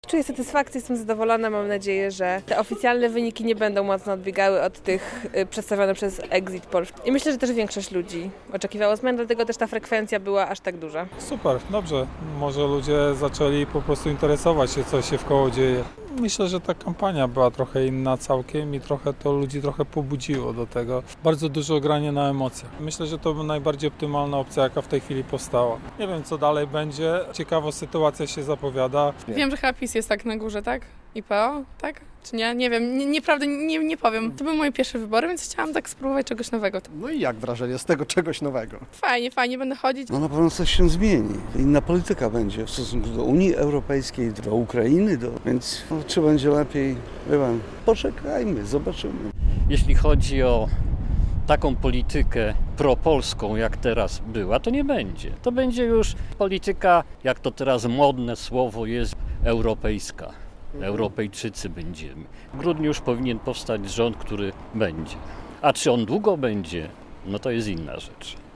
Słuchaj: Jak białostoczanie oceniają dotychczasowe wyniki wyborów? - relacja